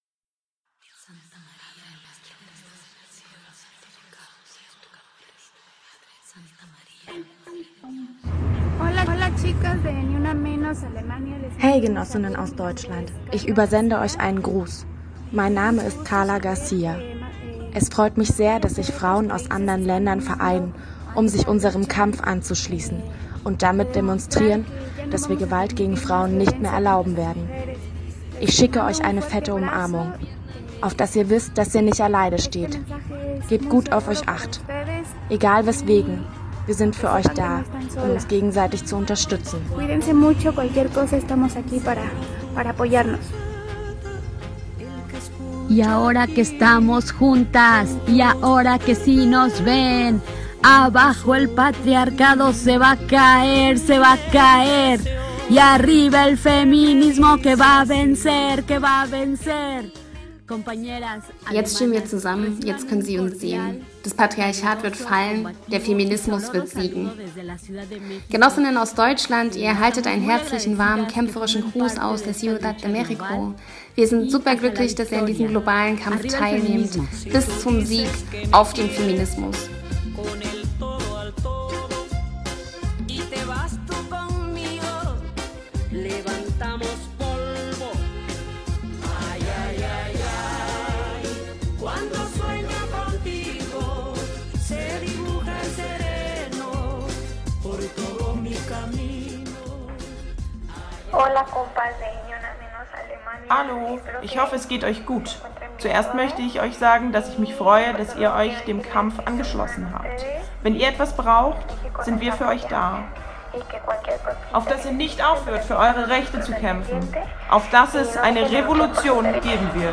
14-Gruswort-Mexico.mp3